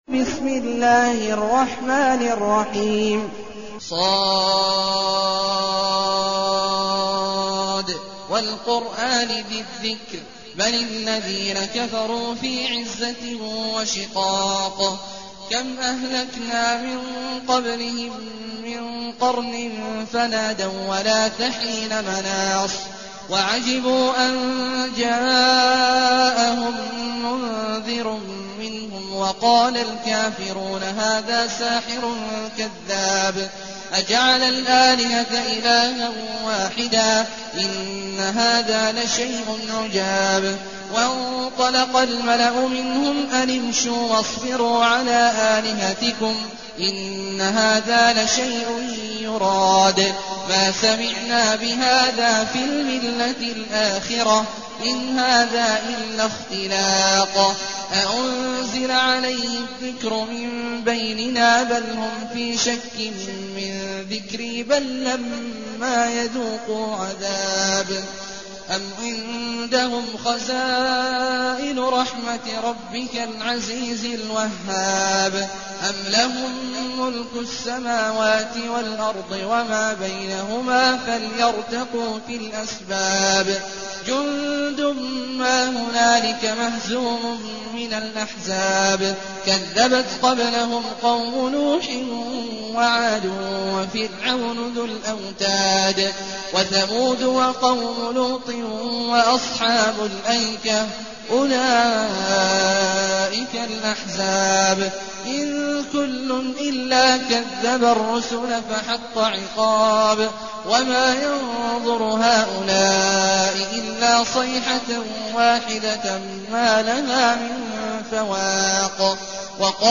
المكان: المسجد الحرام الشيخ: عبد الله عواد الجهني عبد الله عواد الجهني ص